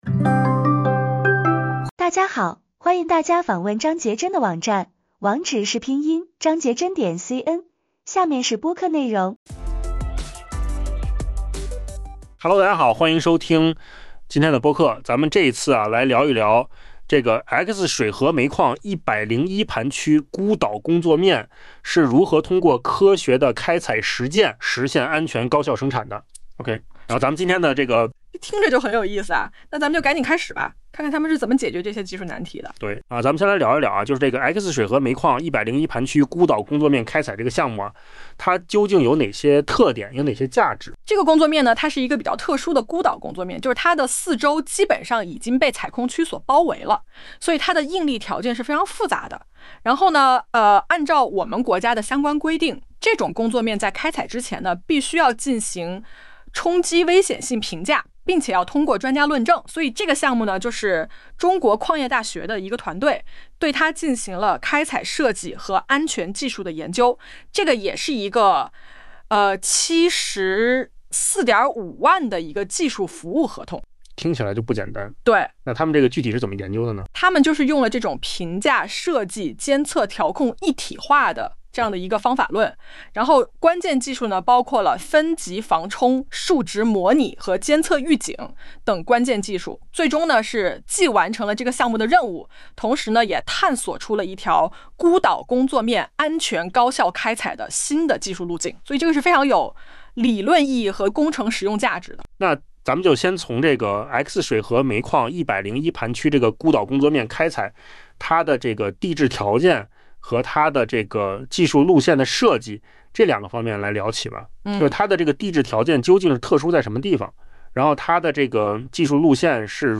音频讲解：X水河煤矿101盘区孤岛工作面的科学开采实践